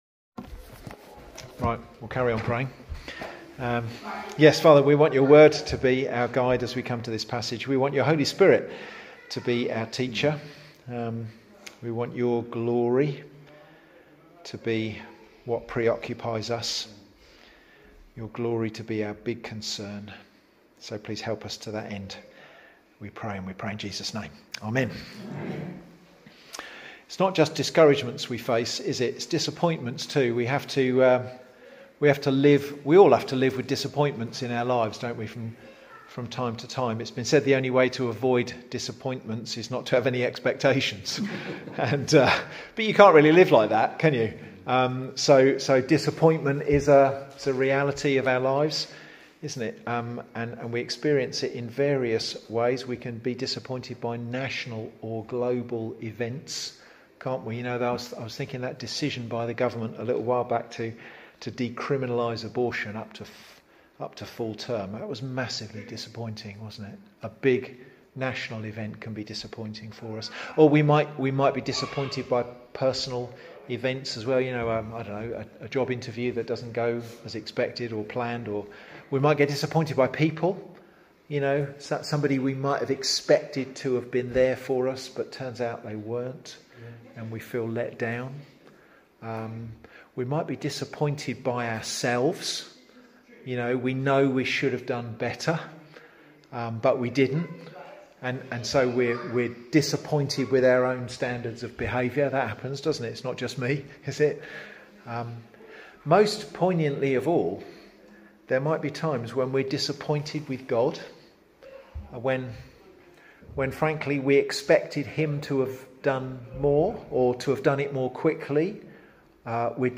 Away Day Sermon 3